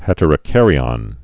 (hĕtər-ə-kărē-ŏn, -ən)